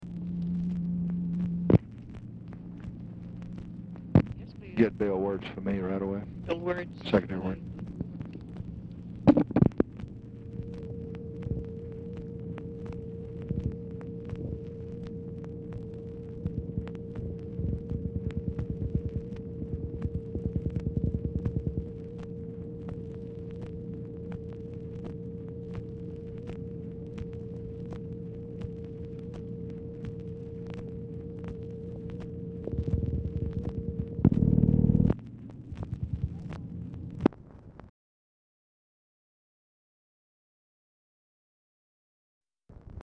Telephone conversation
Format Dictation belt
White House Telephone Recordings and Transcripts Speaker 1 LBJ Speaker 2 TELEPHONE OPERATOR